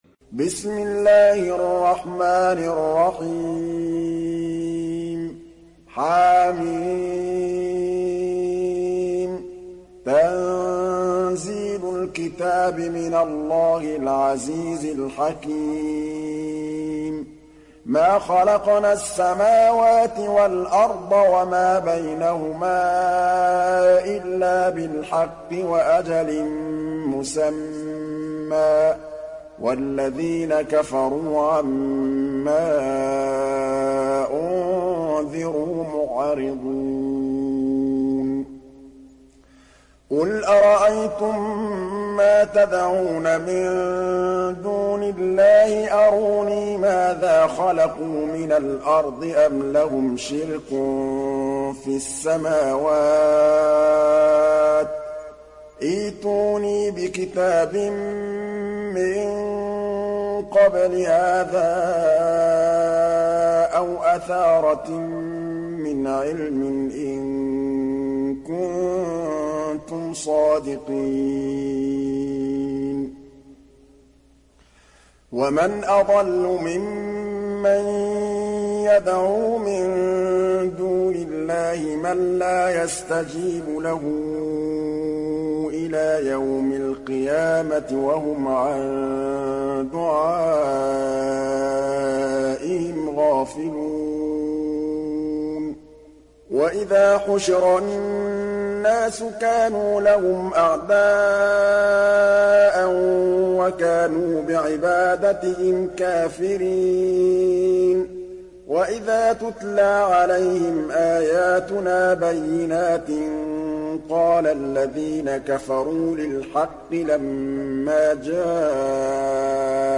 Surat Al Ahqaf Download mp3 Muhammad Mahmood Al Tablawi Riwayat Hafs dari Asim, Download Quran dan mendengarkan mp3 tautan langsung penuh